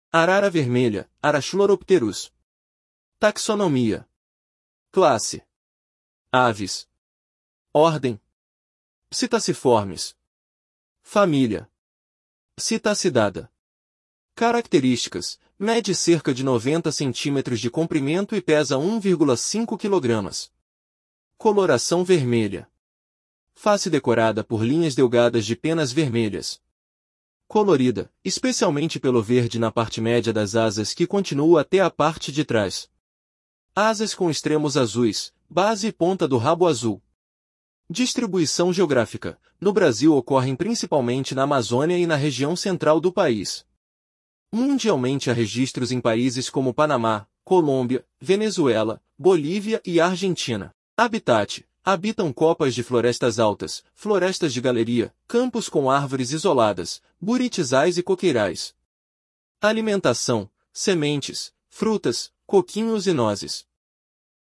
Arara-vermelha (Ara chloropterus)
Se comunicam de forma complexa por meio de vocalizações barulhentas.